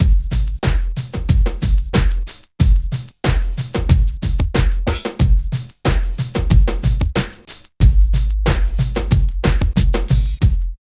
100 LOOP  -L.wav